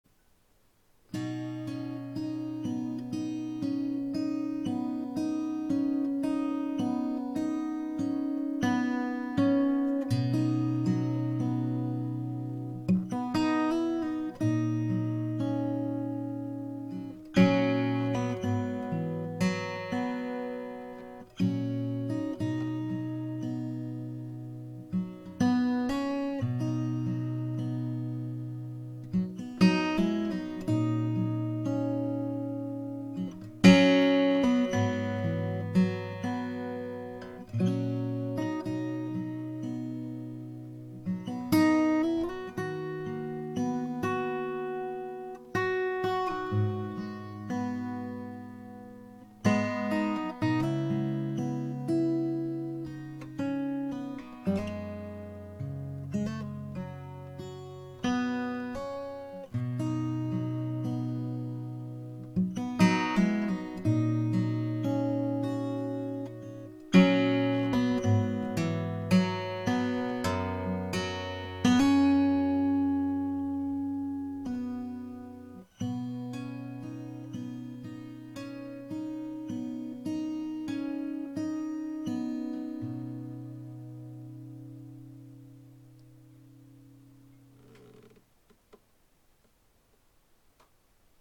今回は録音方法を少し変更し，iPodNANOで録音して，m4aファイルの前後切っただけのそのままです。
Ｌ－００のオープンＧカポ４ もしも，m4aのファイルを再生できない方はiTunesをインストールするか。
全くノンリバーブでそのまま部屋で録音しただけですよ。